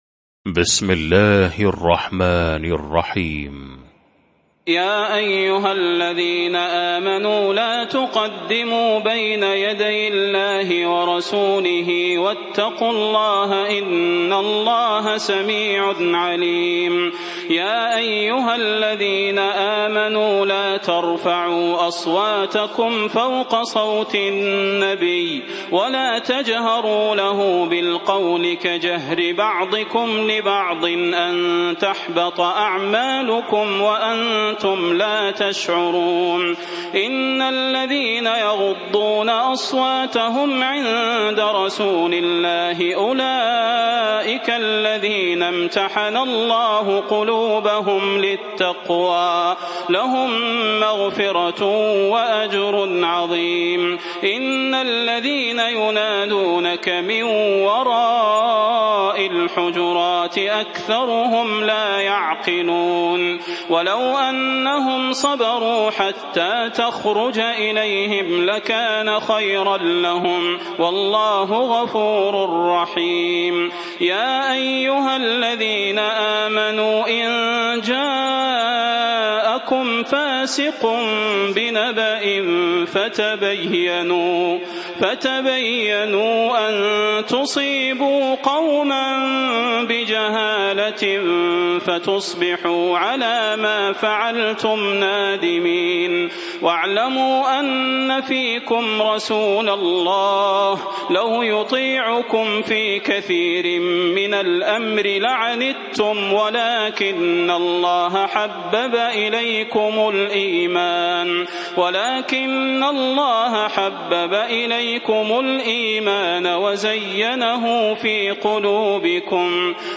المكان: المسجد النبوي الشيخ: فضيلة الشيخ د. صلاح بن محمد البدير فضيلة الشيخ د. صلاح بن محمد البدير الحجرات